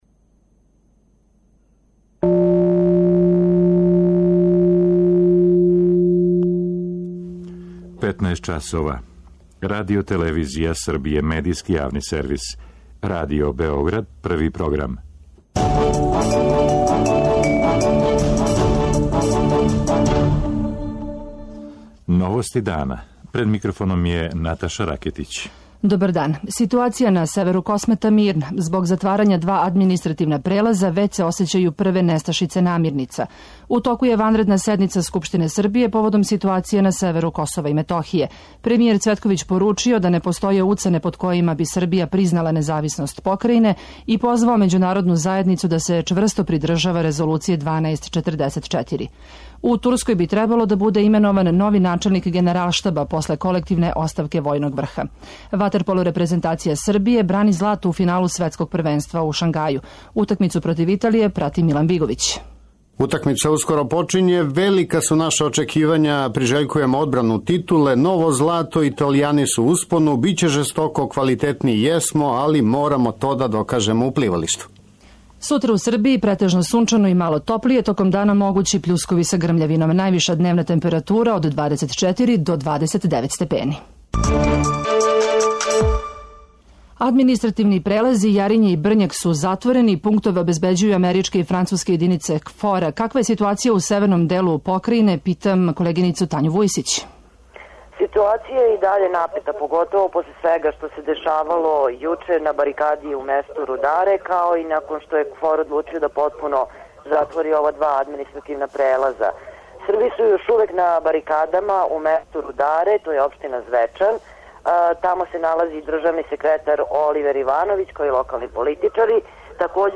О ситуацији на северу Косова и Метохије извештавају наши репортери.